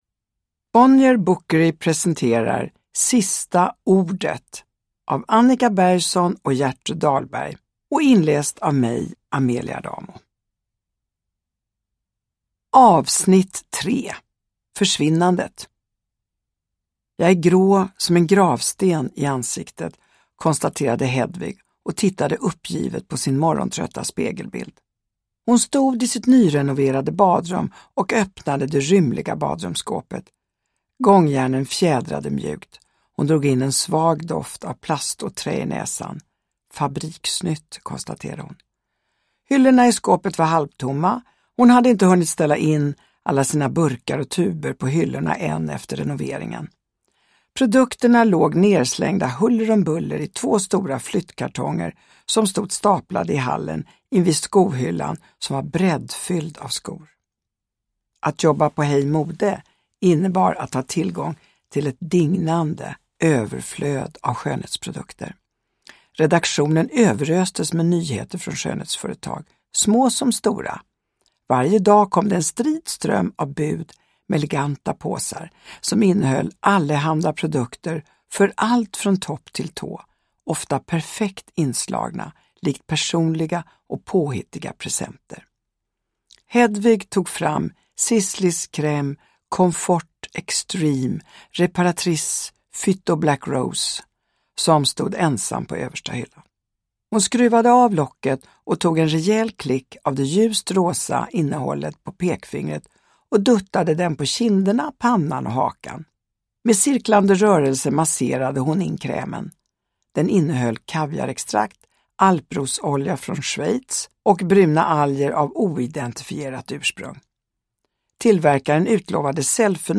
Sista ordet. S1E3, Försvinnandet – Ljudbok – Laddas ner
Uppläsare: Amelia Adamo